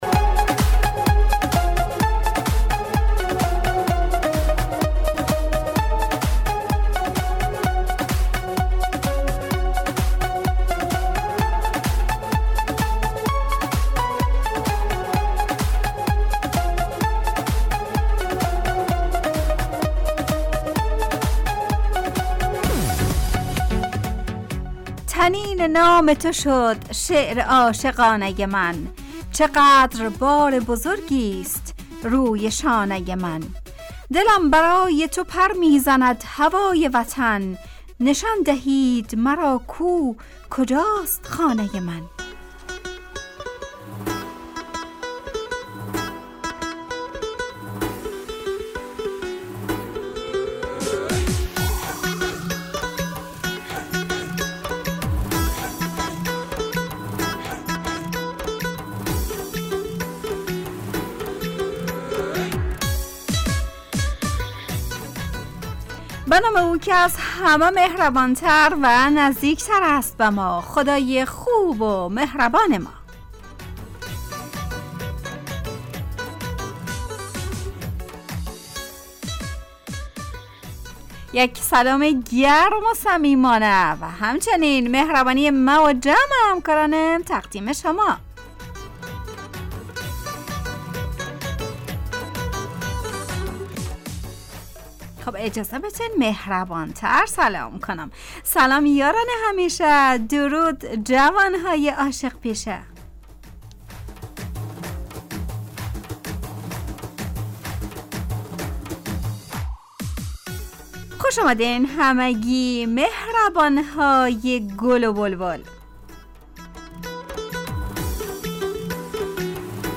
روی موج جوانی، برنامه شادو عصرانه رادیودری.
همراه با ترانه و موسیقی مدت برنامه 55 دقیقه . بحث محوری این هفته (مهربانی) تهیه کننده